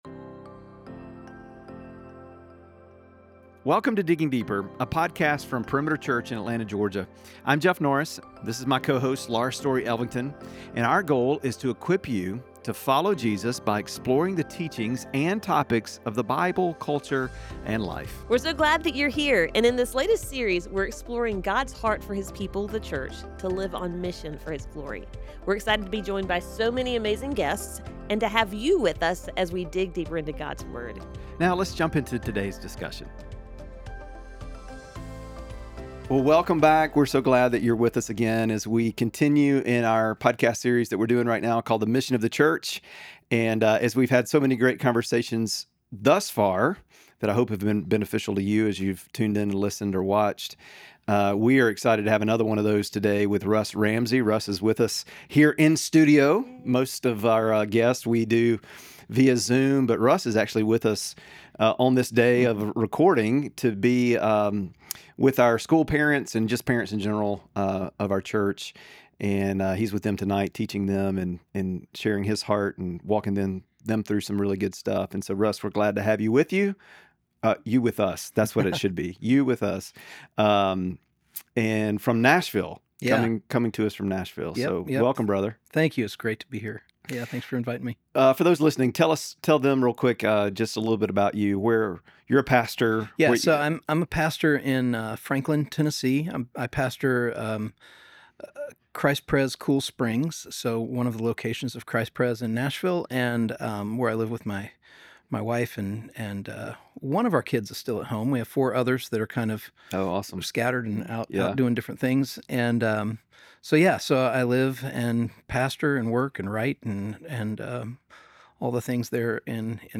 Listen to this powerful conversation on faith, storytelling, and what it means to be the body of Christ in the world today.